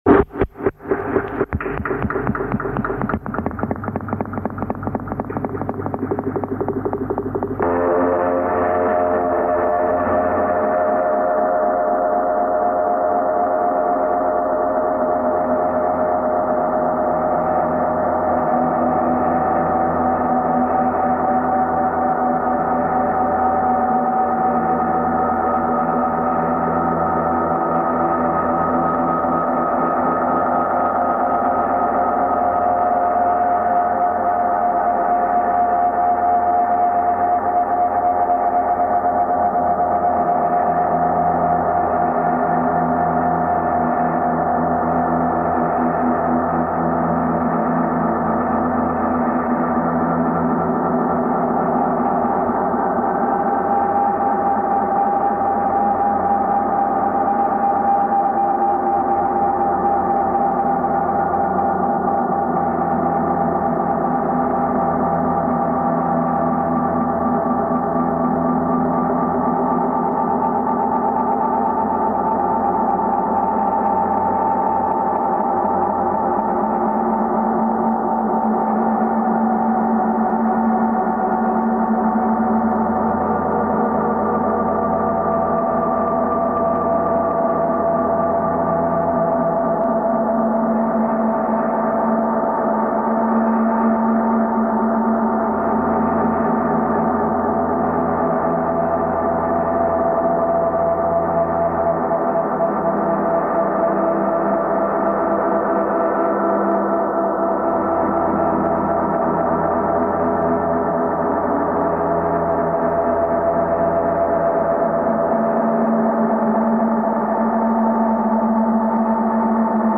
two bloody clumps of foggy echoes and dark reverberations